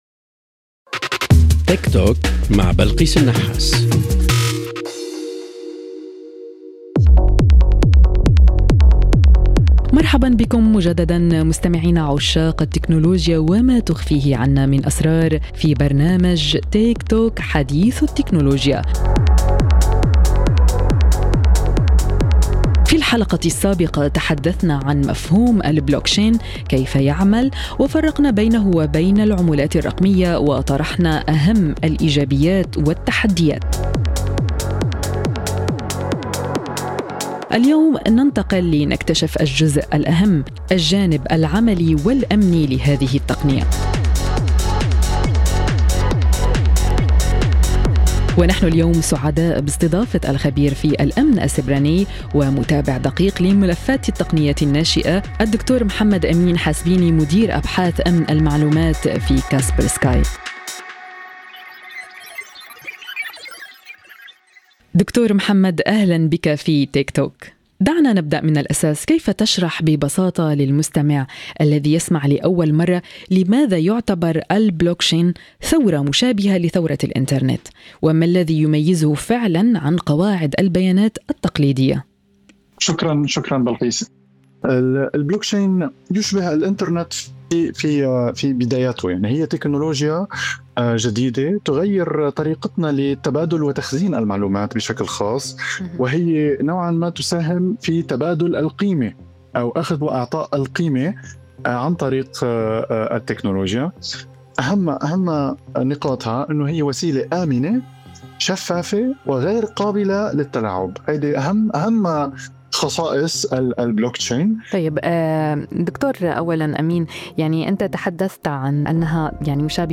في حوار غني بالتفاصيل التقنية والأمنية، يشرح الخبير كيف تعمل البلوكشين في العمق، ولماذا تكمن المخاطر أحيانًا في المستخدم وليس في التقنية ذاتها.